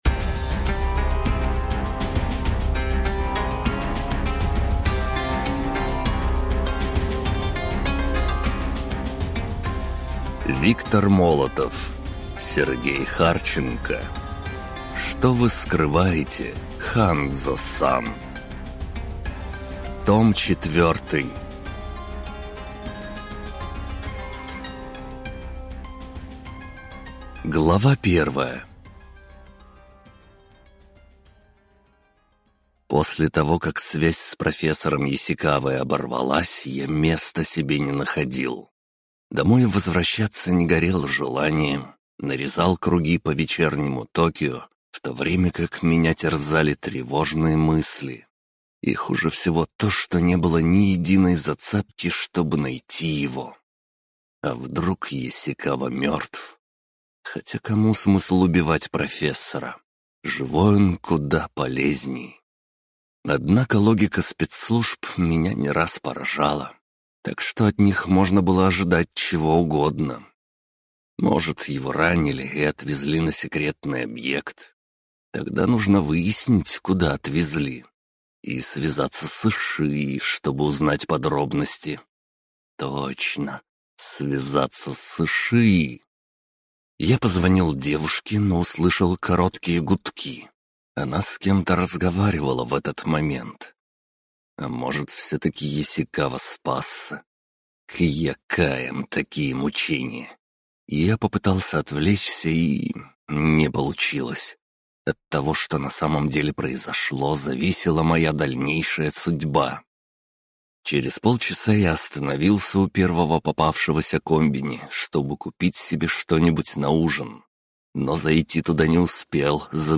Звезды над болотом (слушать аудиокнигу бесплатно) - автор Валентин Пикуль